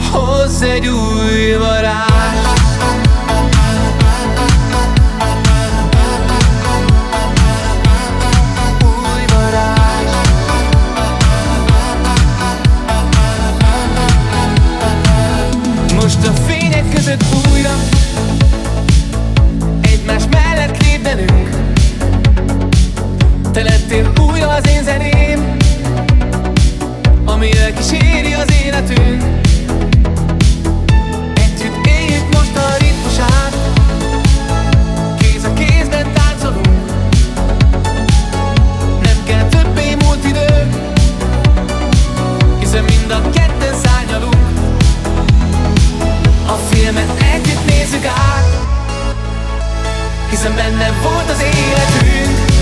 80’s Remix Extended Version